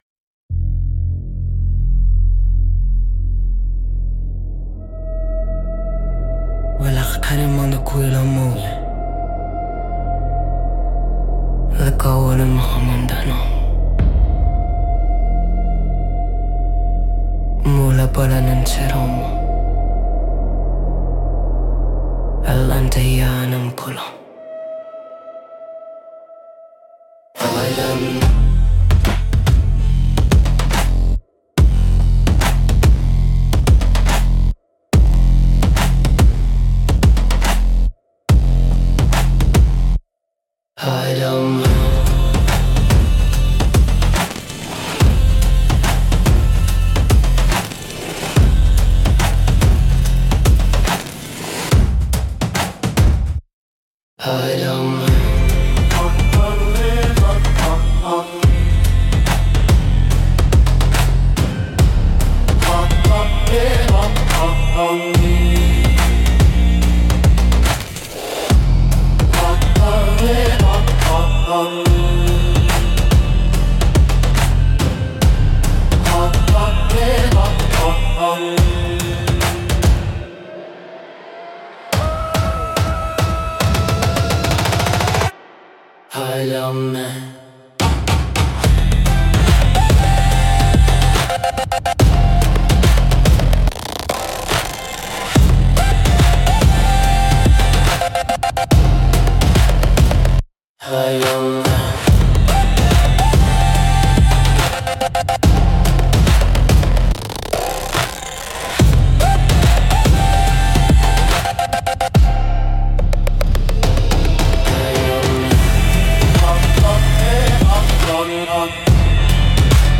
Instrumental - Eclipse Ceremony -2.24